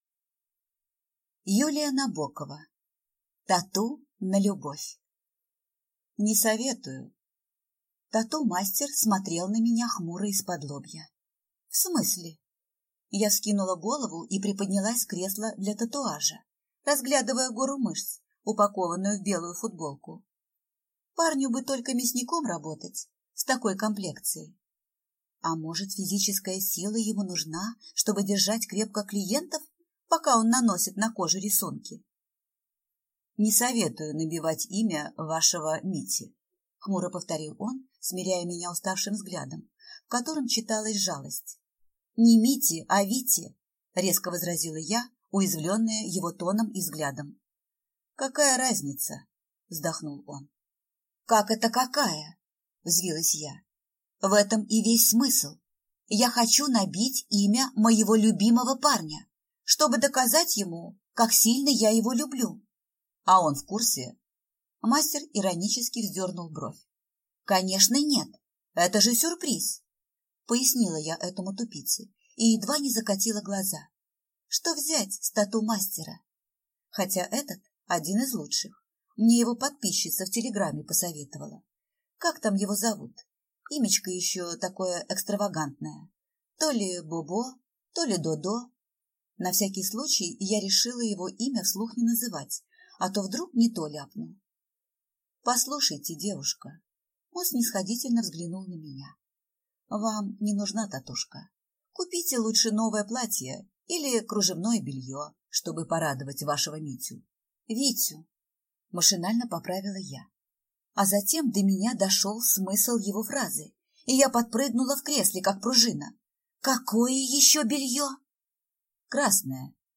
Aудиокнига Тату на любовь